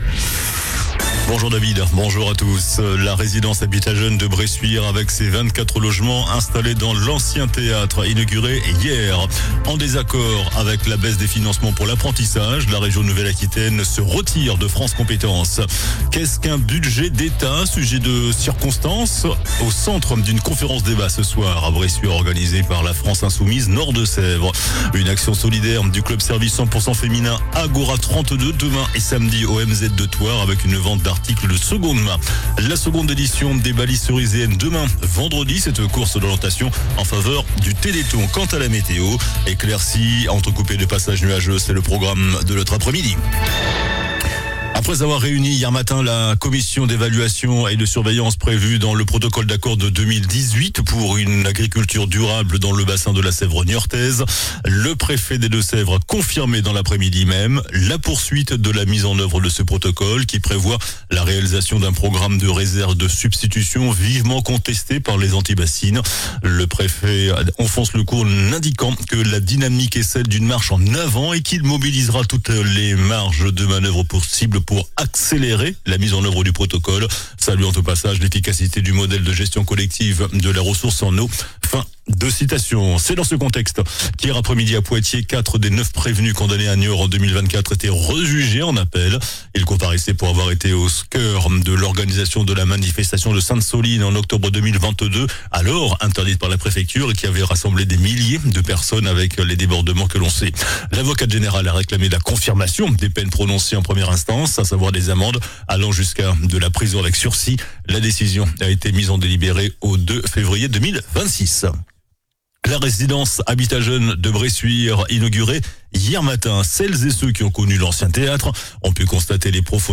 JOURNAL DU JEUDI 04 DECEMBRE ( MIDI )